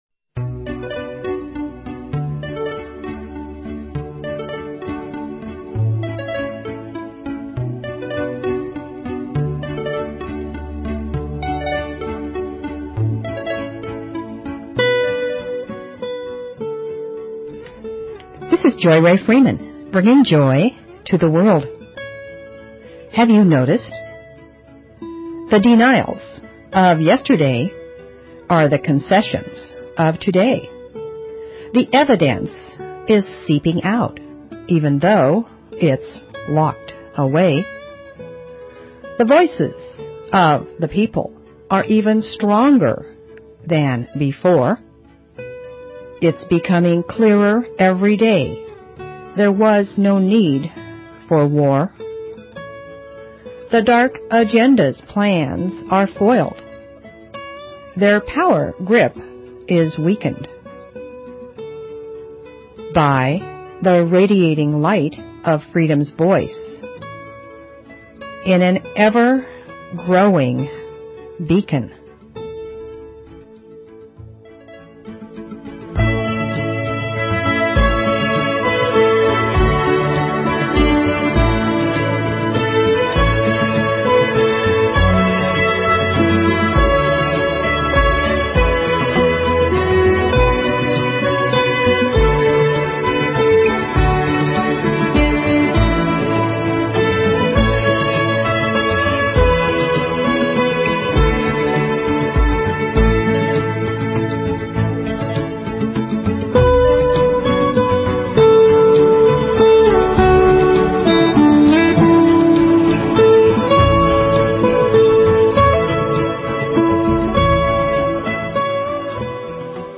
Talk Show Episode, Audio Podcast, Joy_To_The_World and Courtesy of BBS Radio on , show guests , about , categorized as
Music, poetry, affirmations, stories, inspiration . . .